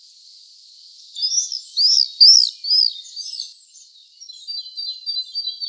Apus apus - Swift - Rondone
- COMMENT: These calls are different and slightly lower pitched from the previous ones. Background: Great tit song.